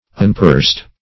Search Result for " unpursed" : The Collaborative International Dictionary of English v.0.48: Unpursed \Un*pursed"\, a. [1st pref. un- + purse + -ed.]
unpursed.mp3